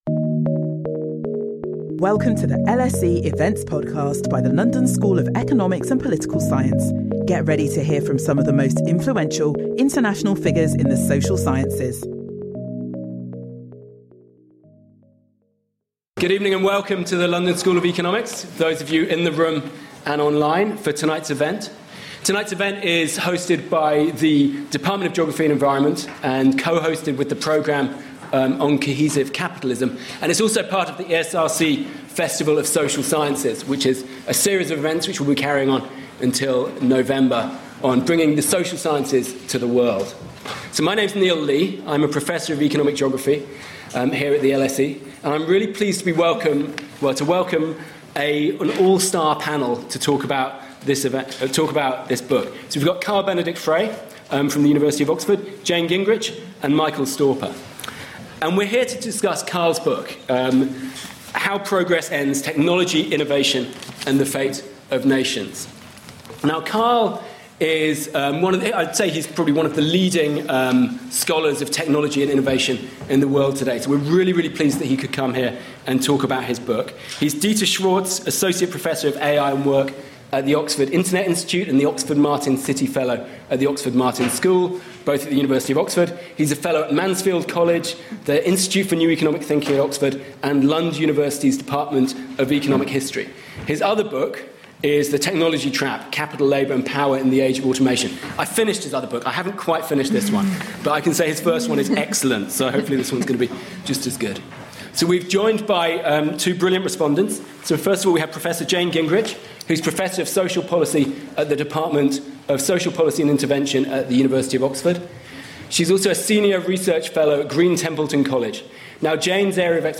In this event, Carl Benedikt Frey – one of the leading scholars of technology and the economy – will discuss his new book, How Progress Ends.